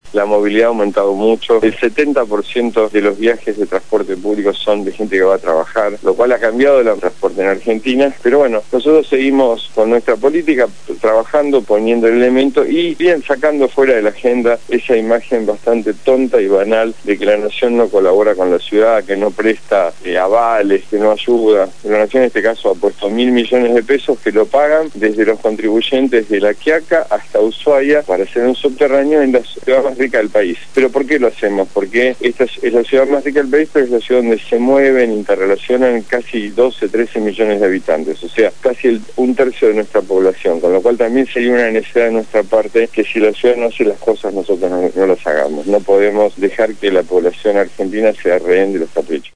Lo dijo Juán Pablo Schiavi, secretario de Transporte de la Nación, en el programa «Punto de partida» (Lunes a viernes de 7 a 9 de la mañana), por Radio Gráfica FM 89.3